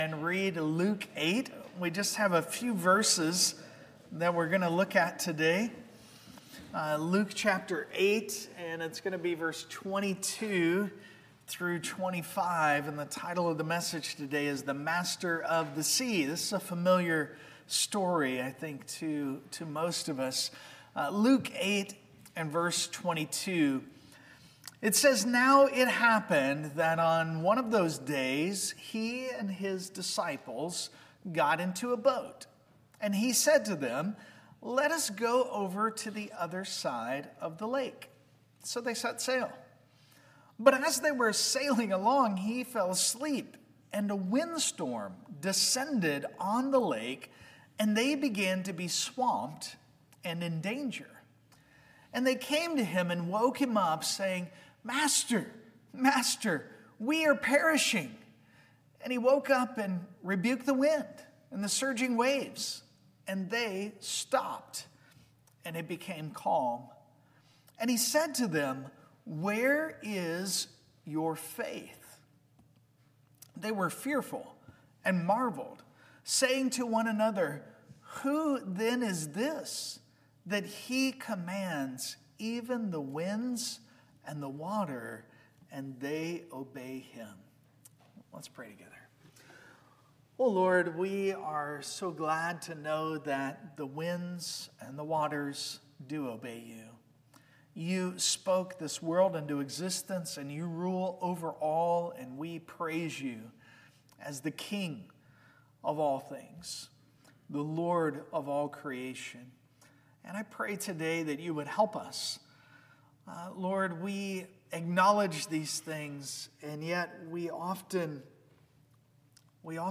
Luke Passage: Luke 8:22-25 Service Type: Sunday Morning « Listen Up!